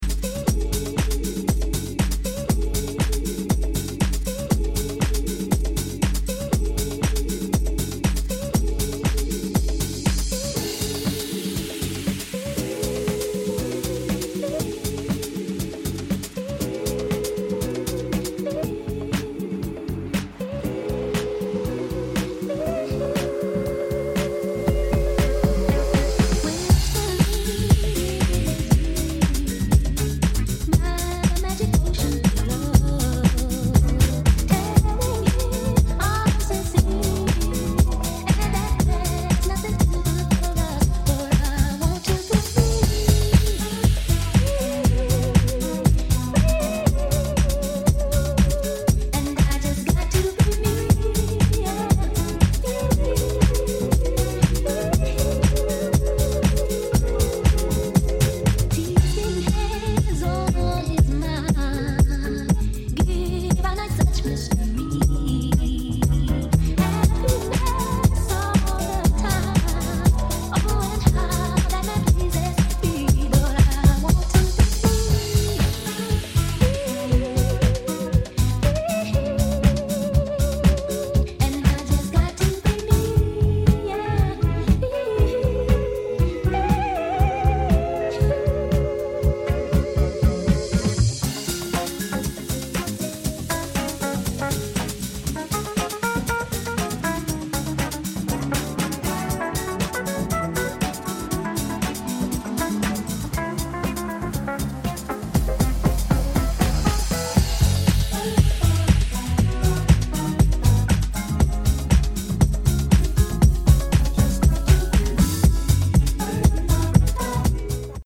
Disco / Balearic Edit